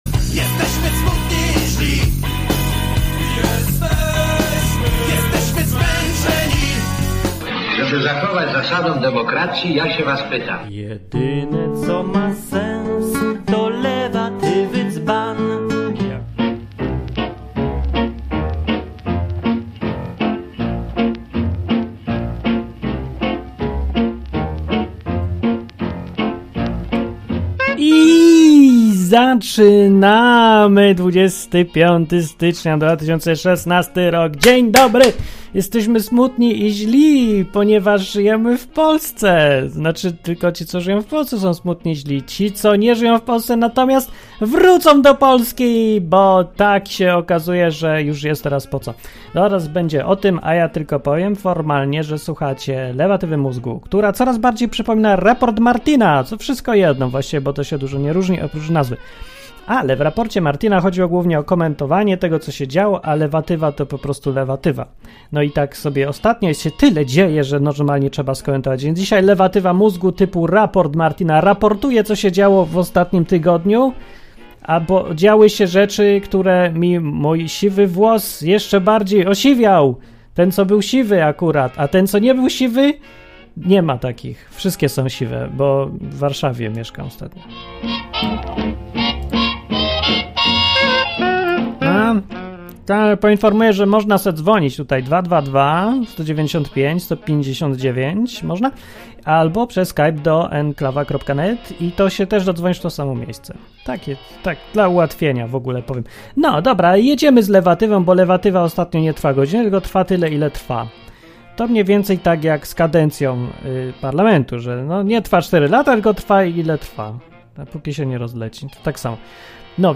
O tym wszystkim dowiesz się w sposób wesoły i ironiczny z Lewatywy Mózgu!
Program satyryczny, rozrywkowy i edukacyjny.